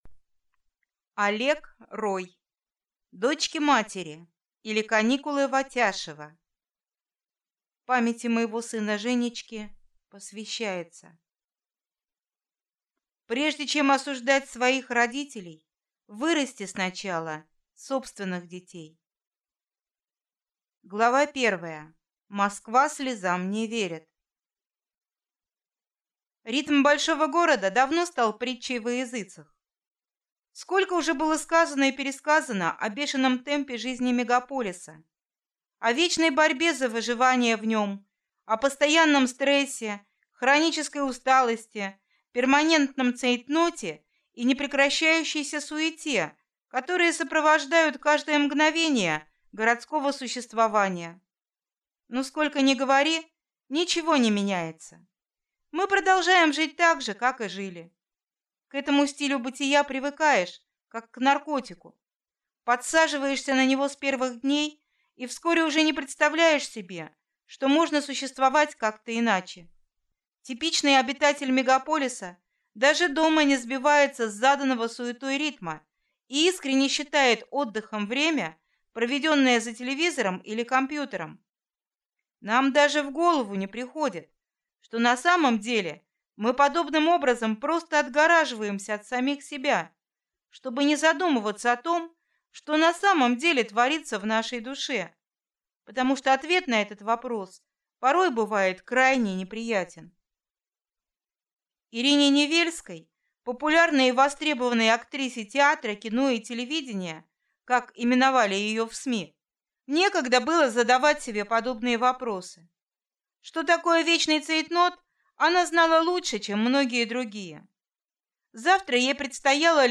Аудиокнига Дочки-матери, или Каникулы в Атяшево | Библиотека аудиокниг